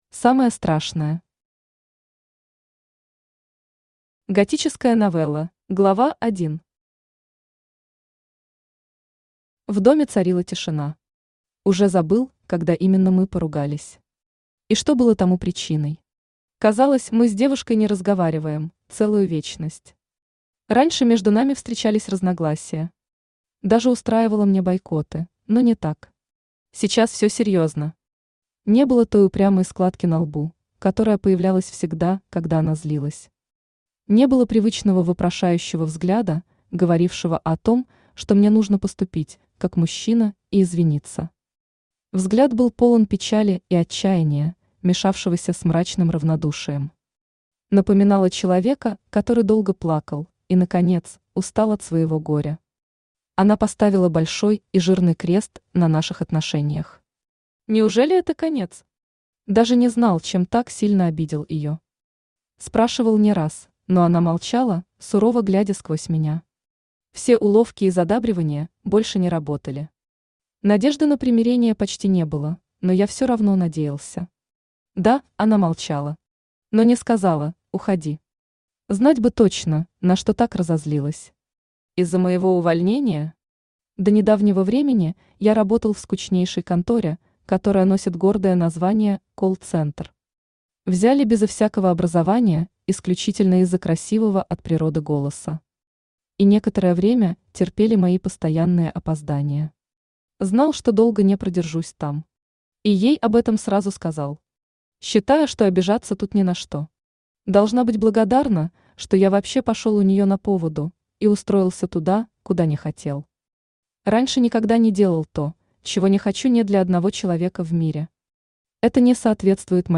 Аудиокнига Самое страшное | Библиотека аудиокниг
Aудиокнига Самое страшное Автор Юлия Валерьевна Шаманская Читает аудиокнигу Авточтец ЛитРес.